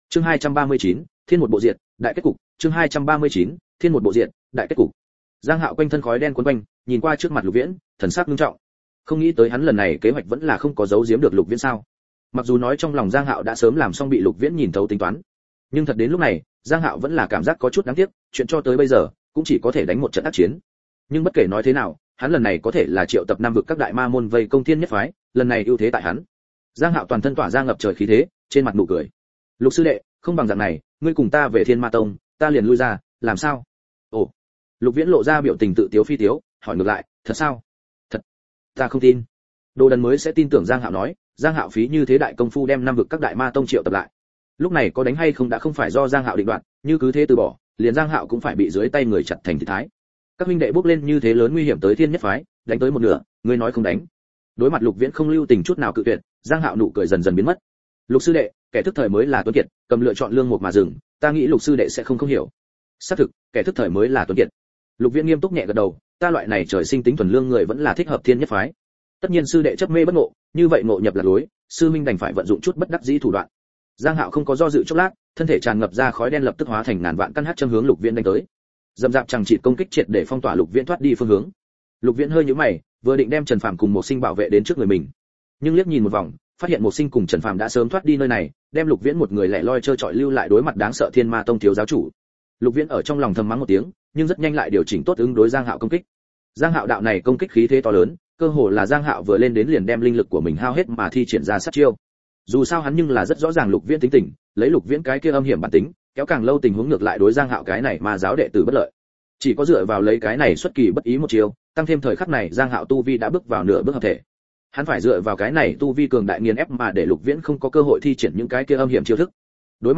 Người Nào Dạy Ngươi Coi Thiên Mệnh Chi Tử Là Boss Nuôi Audio - Nghe đọc Truyện Audio Online Hay Trên RADIO TRUYỆN FULL